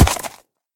assets / minecraft / sounds / mob / horse / land.ogg
land.ogg